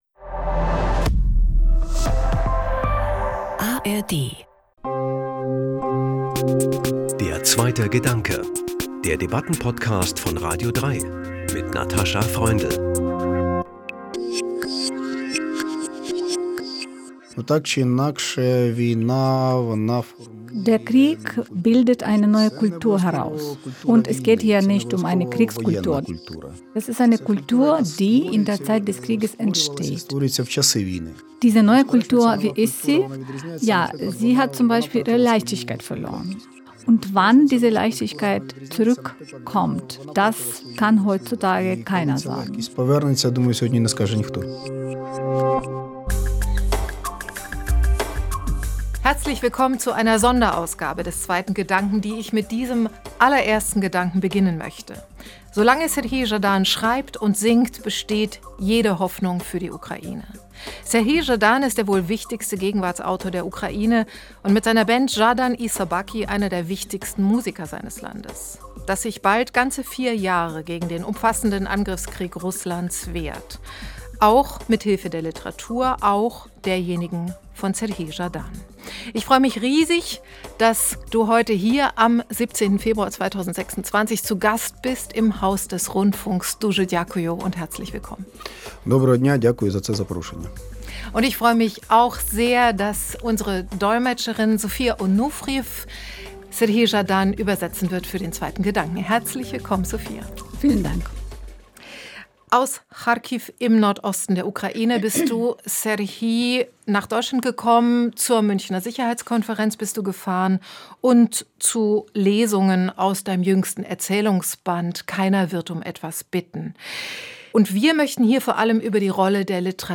Deutsche Fassung.